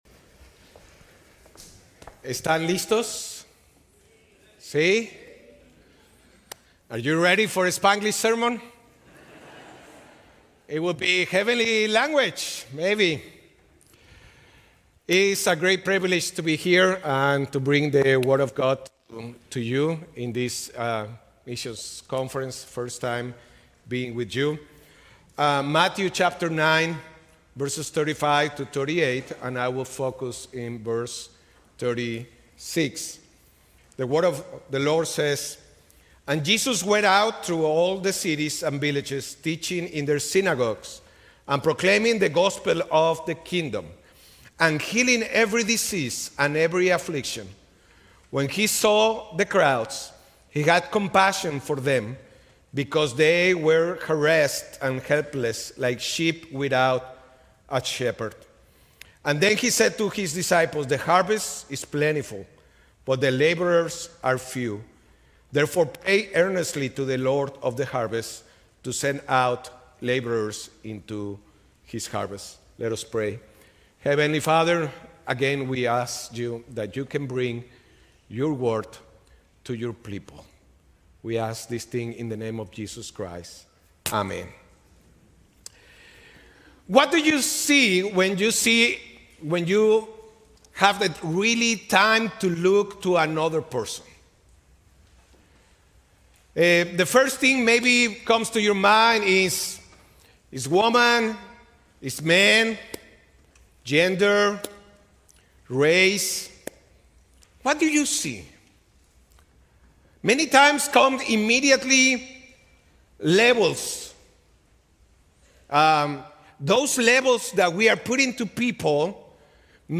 From Series: "2026 Missions Conference"